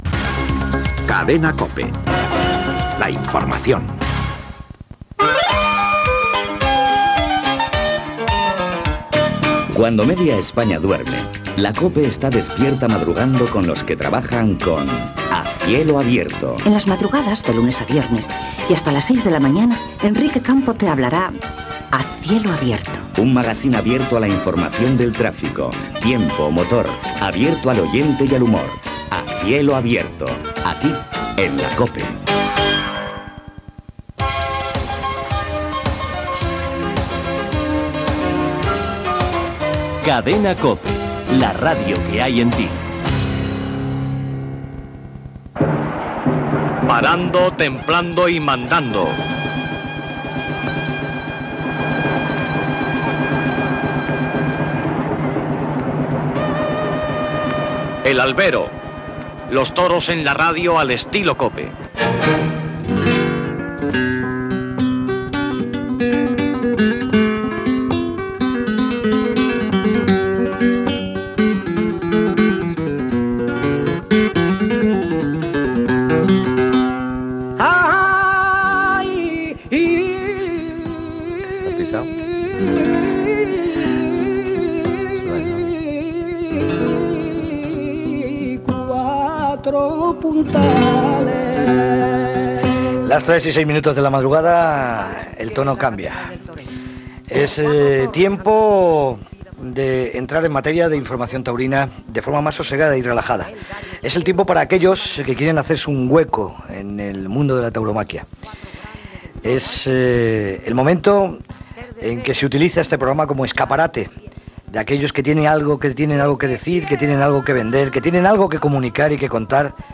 Indicatiu, promo "A cielo abierto", indicatiu, careta del programa i presentació de l'hora donant pas al tema de les novellades de braus.
Informatiu